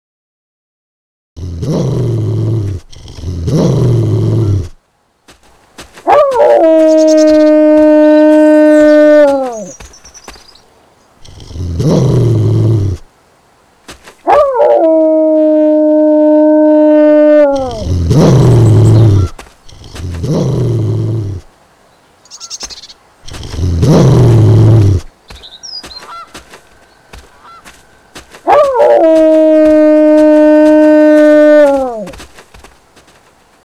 Wolf Howling